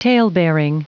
Prononciation du mot talebearing en anglais (fichier audio)
Prononciation du mot : talebearing